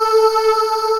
Index of /90_sSampleCDs/Techno_Trance_Essentials/CHOIR
64_11_voicesyn-A.wav